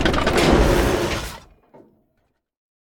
tank-brakes-3.ogg